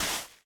Minecraft Version Minecraft Version snapshot Latest Release | Latest Snapshot snapshot / assets / minecraft / sounds / block / suspicious_sand / place5.ogg Compare With Compare With Latest Release | Latest Snapshot